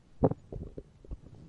Hunger Noises 01 » Hunger NoisesLong
描述：Sounds of a human stomach. Captured with Zoom H2, edited with Audacity.
标签： empty human burp disgusting hungry blurp guts hunger stomach
声道立体声